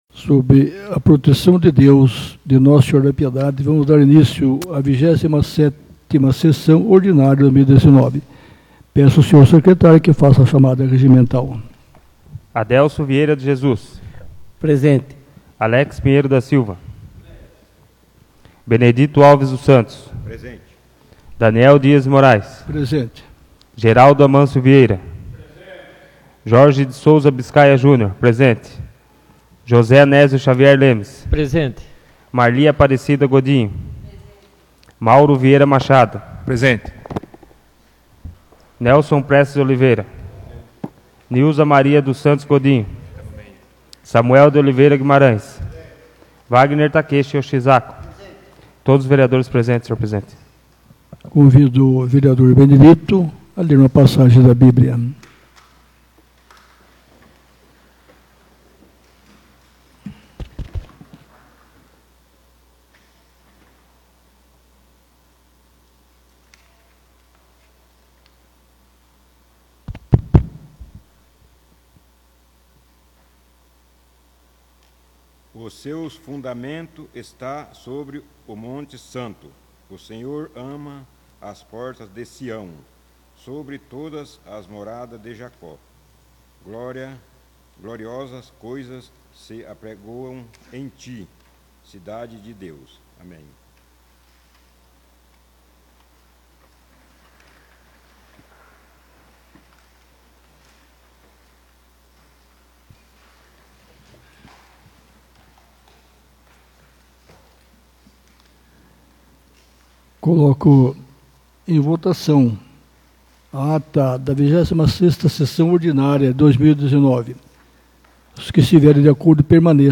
27ª Sessão Ordinária de 2019 — Câmara Municipal de Piedade